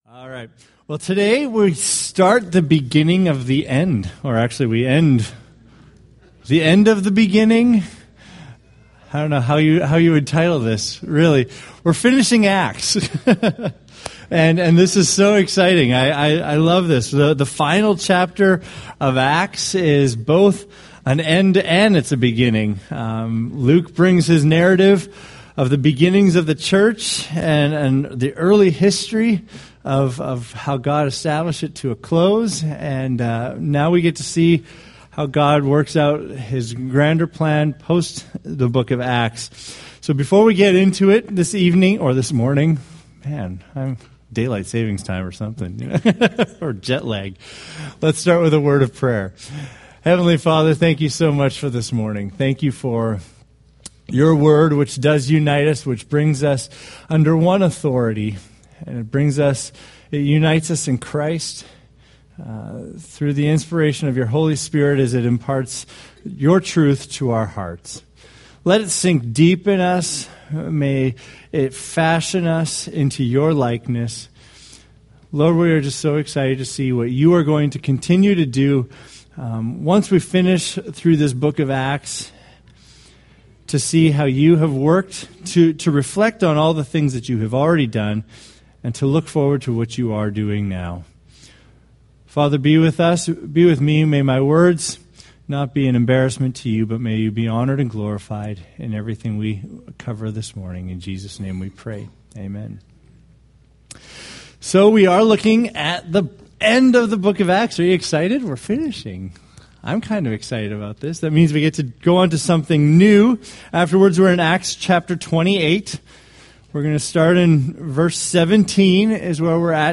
Date: Jan 18, 2015 Series: Acts Grouping: Sunday School (Adult) More: Download MP3